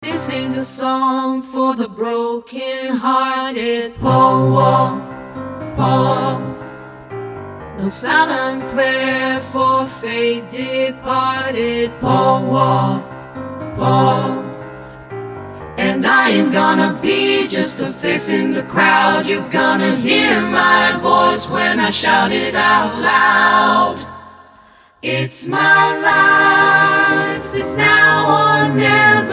Deze energieke koorzetting
A powerful SAB arrangement